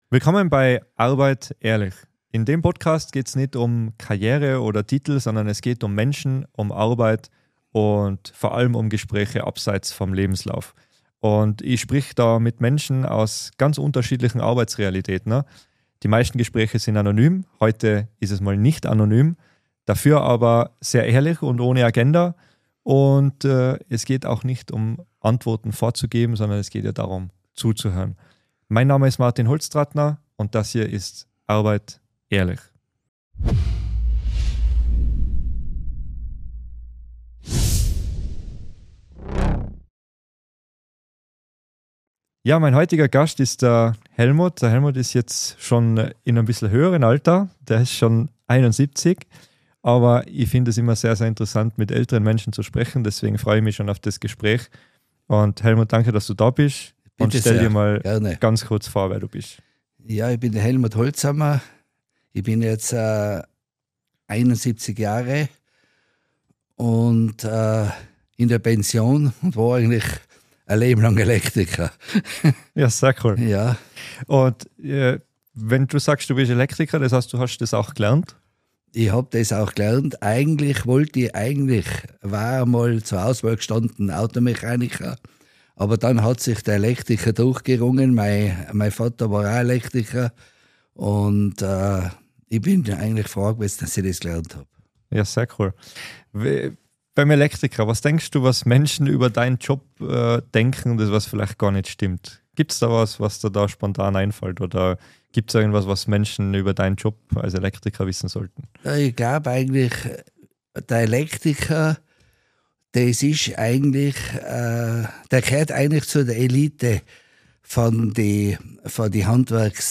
In dieser Folge von Arbeit. Ehrlich. ist unser Gast bereits in der Pension. Er spricht mit uns über - seine Lehrzeit - die frühere Arbeitswelt - die Erziehung von Kindern und warum es ohne Elektriker nicht geht.